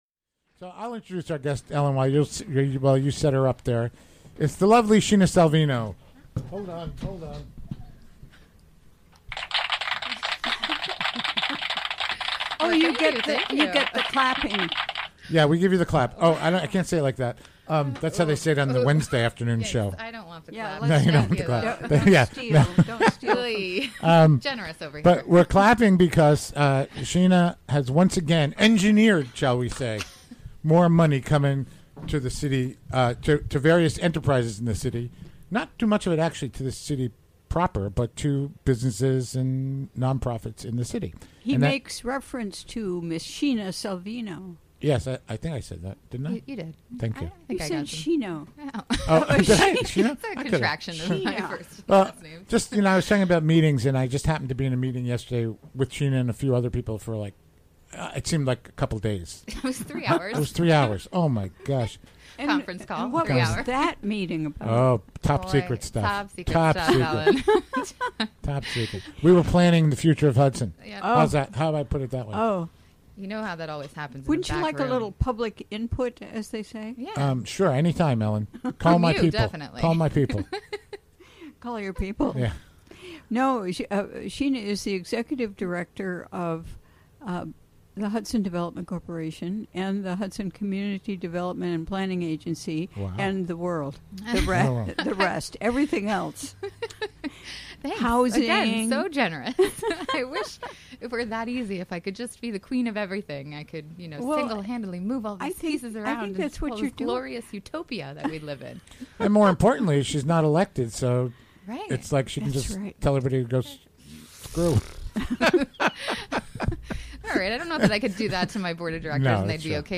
Recorded live during the WGXC Afternoon Show, Thu., Dec. 14.